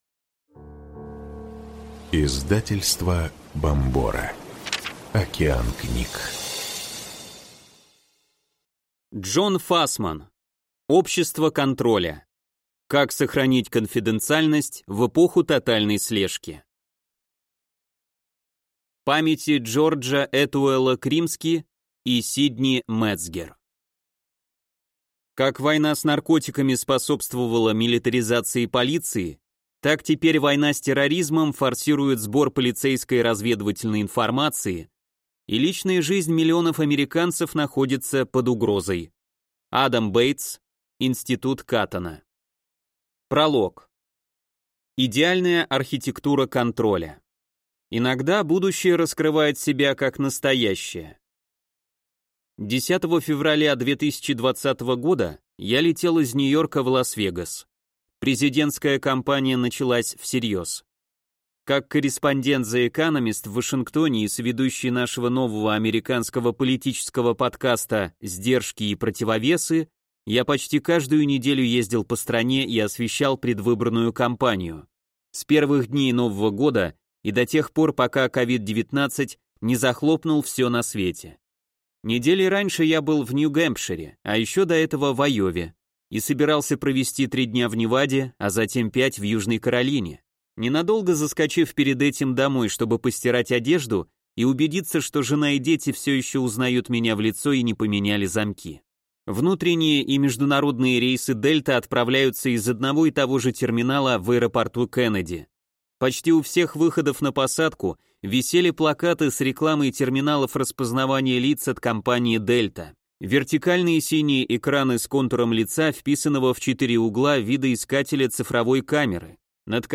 Аудиокнига Общество контроля. Как сохранить конфиденциальность в эпоху тотальной слежки | Библиотека аудиокниг